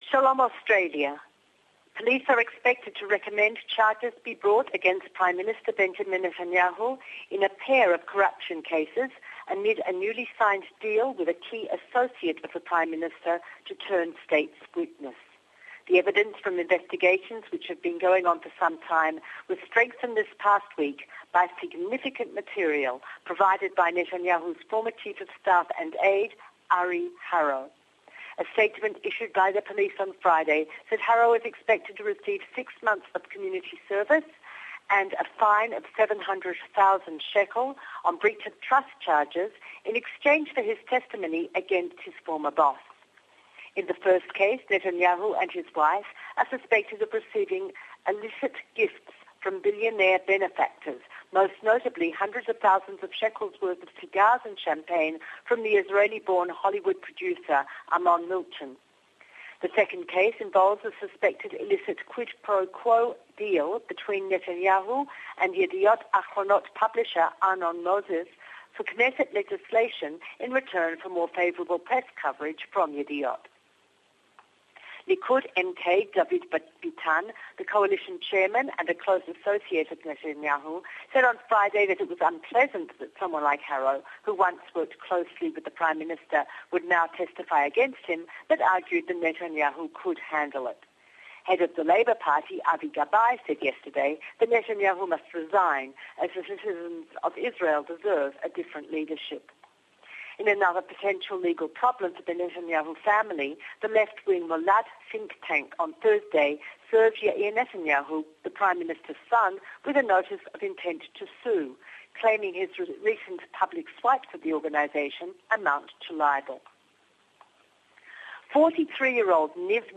weekly report in English from Jerusalem.